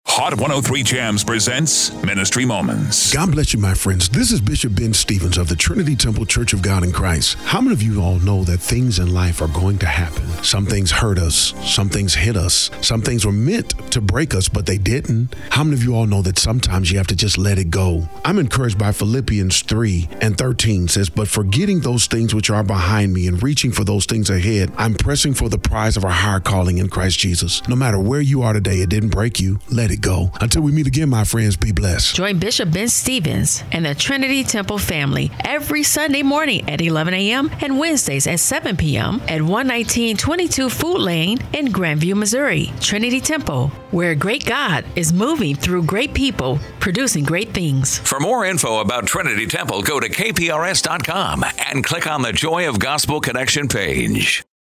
Radio Broadcast